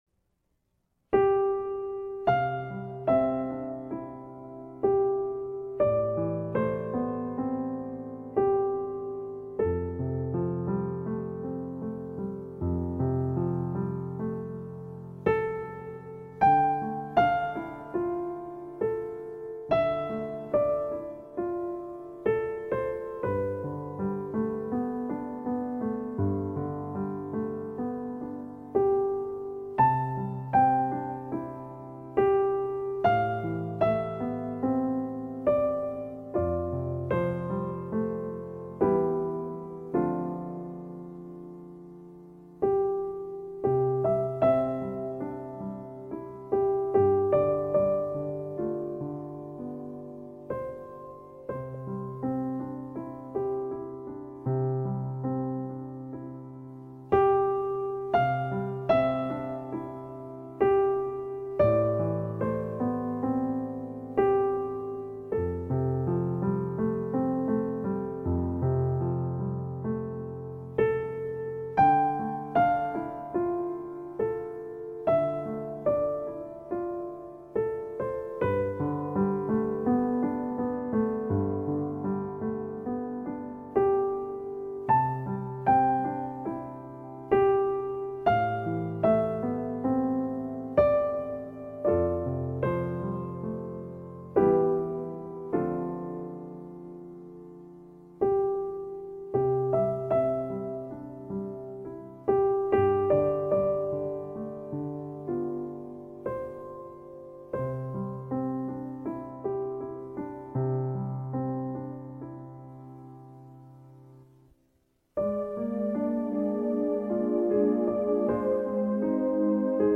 经典钢琴曲